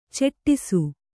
♪ ceṭṭisu